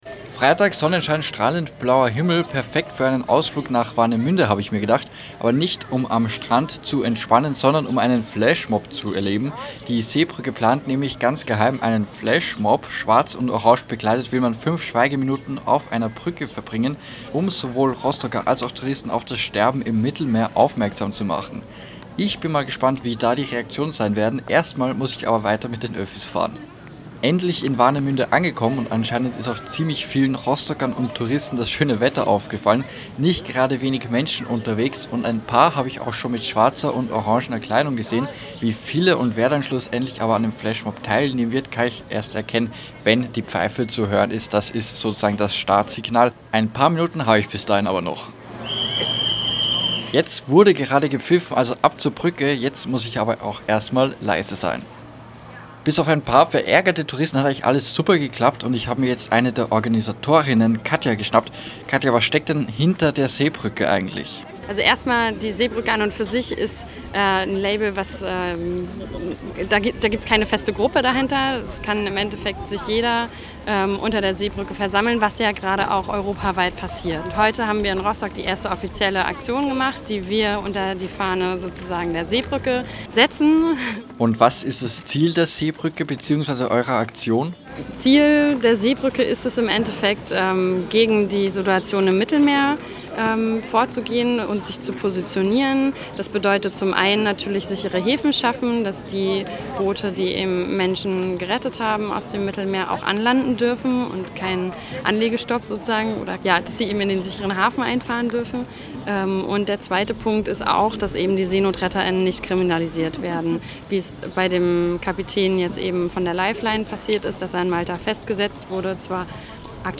Einer unserer Redakteure war unter ihnen und berichtet hier: